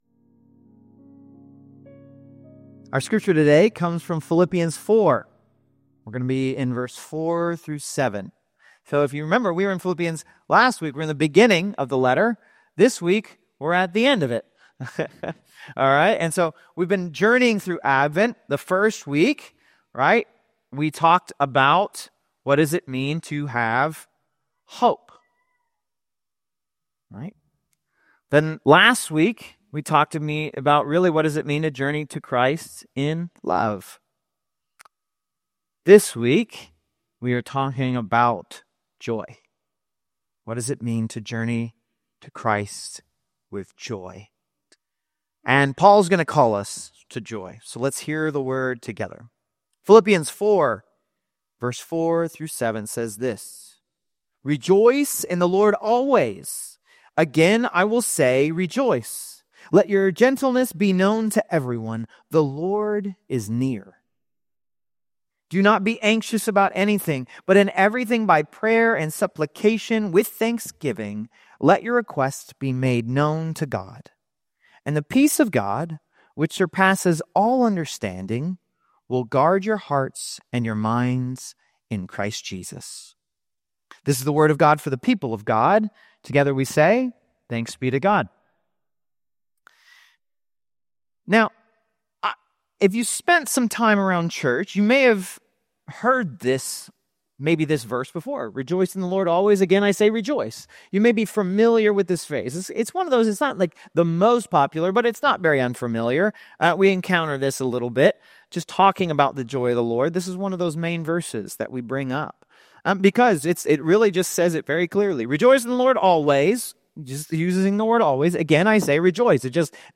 Do Not Be Anxious - First Houston United Methodist Church